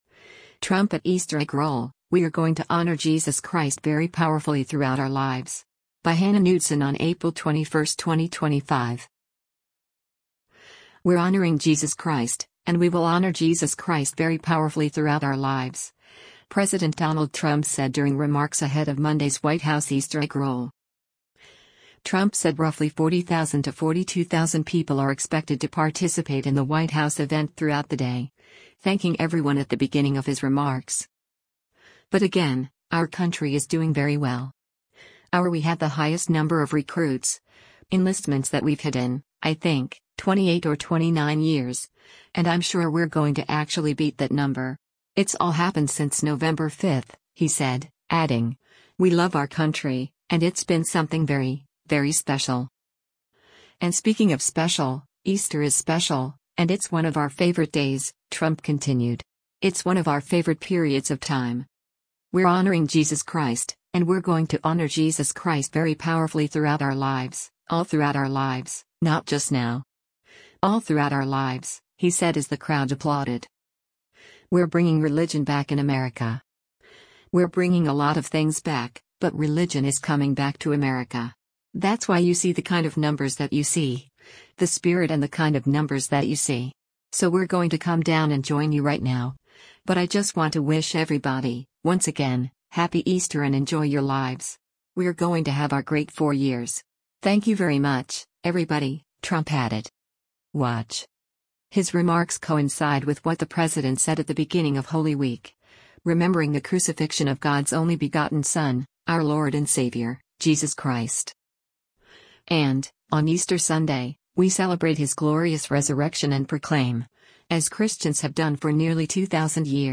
“We’re honoring Jesus Christ,” and we will “honor Jesus Christ very powerfully throughout our lives,” President Donald Trump said during remarks ahead of Monday’s White House Easter Egg Roll.
“We’re honoring Jesus Christ, and we’re going to honor Jesus Christ very powerfully throughout our lives, all throughout our lives — not just now. All throughout our lives,” he said as the crowd applauded.